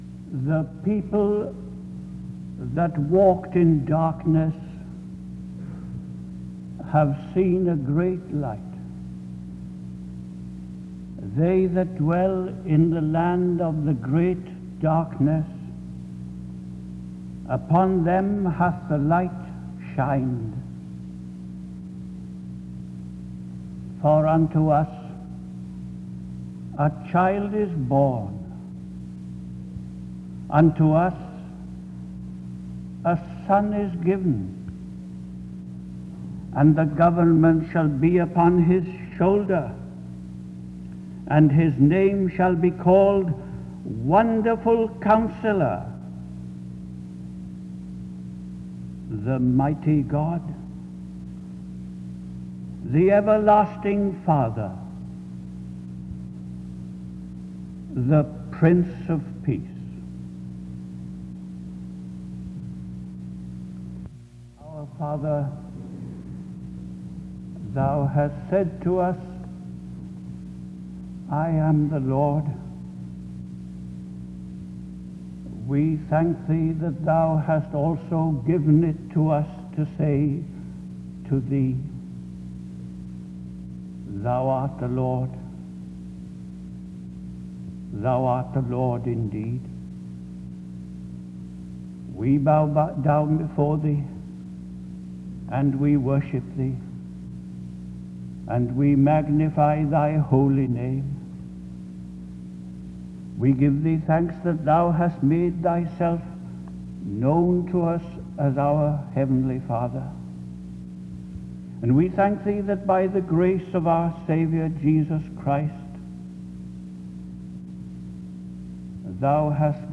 The service begins with a scripture reading from 0:00-1:03. A prayer is offered from 1:05-4:07. Mark 8:27-30 is read from 4:22-5:30.